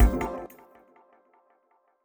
UI sparkle SFX